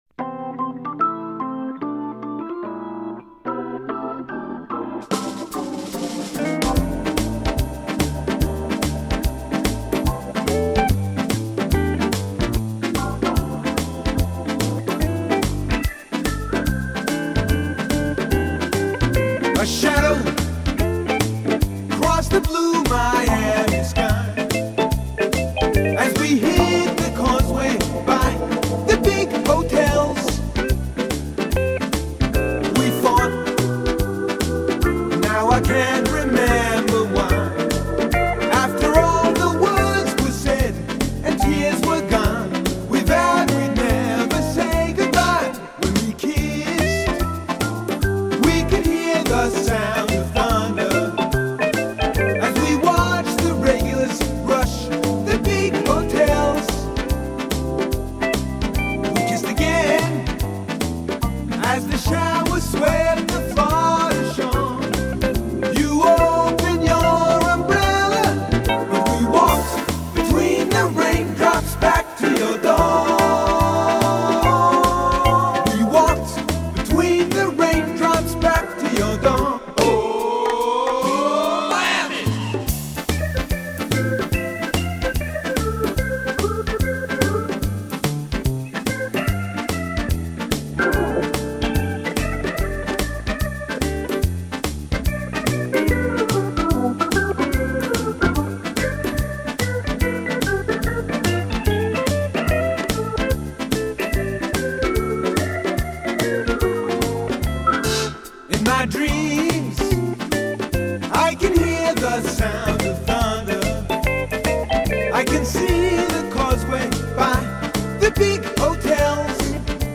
Inauguramos el mes de abril con otra de las canciones para el confinamiento, un poco de jazz rock en la canción del día de 'Herrera en COPE': "Walk between raindrops' de Donald Fagen.